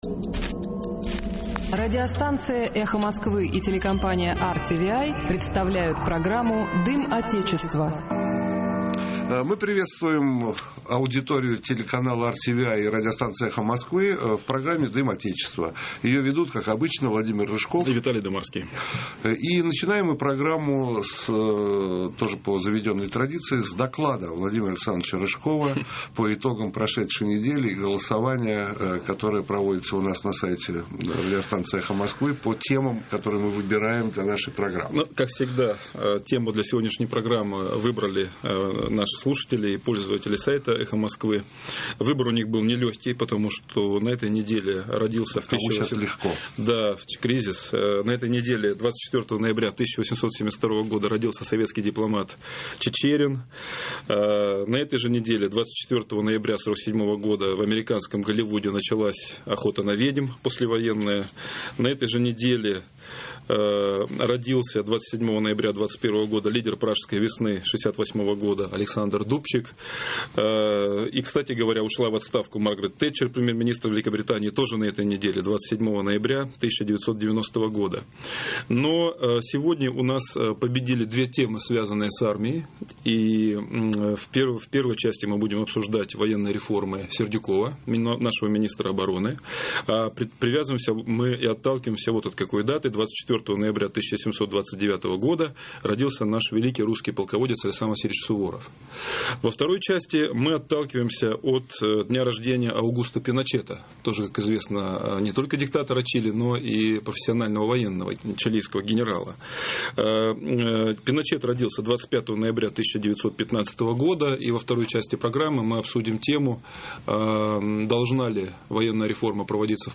В.ДЫМАРСКИЙ: Здравствуйте, в прямом эфире программа «Дым Отечества» и мы, ее ведущие - Владимир Рыжков.